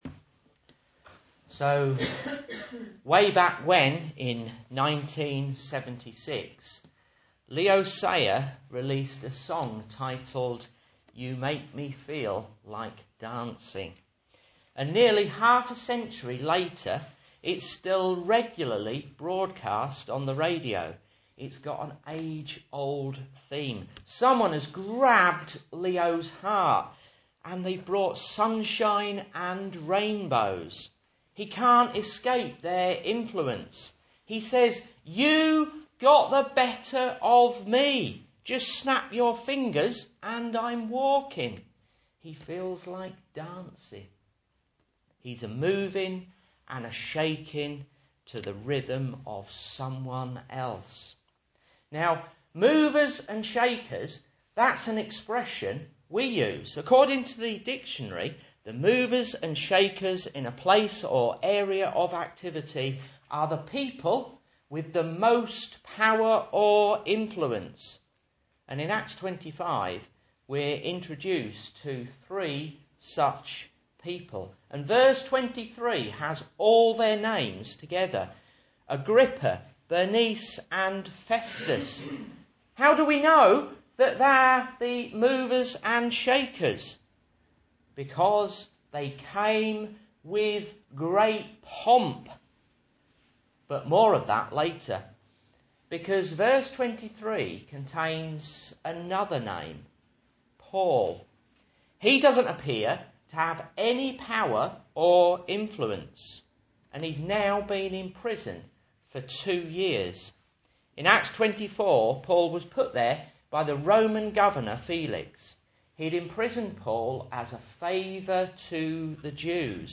Message Scripture: Acts 25:1-22 | Listen